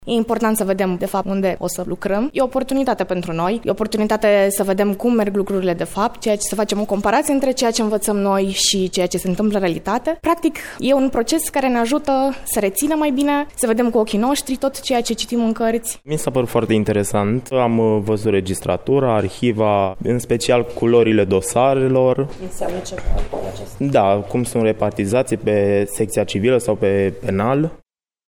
Președintele Tribunalului pentru Minori și Familie Brașov, judecător Gabriela Chihaia.